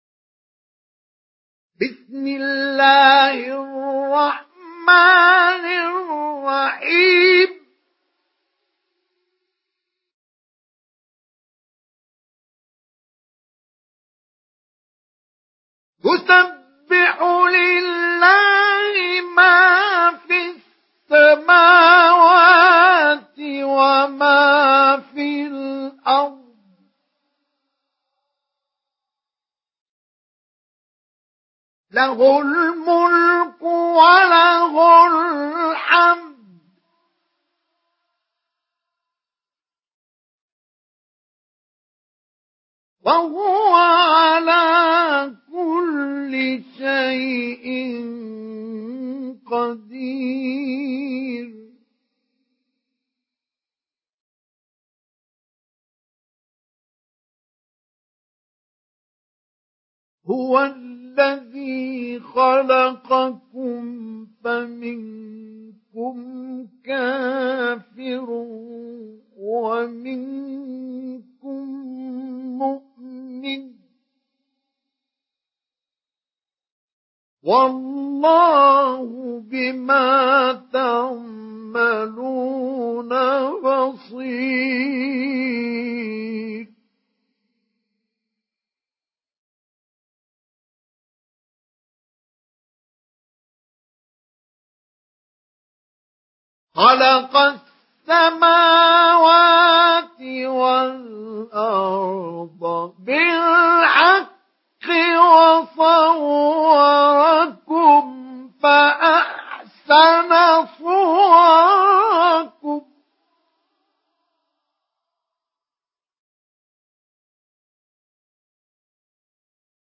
Surah At-Taghabun MP3 by Mustafa Ismail Mujawwad in Hafs An Asim narration.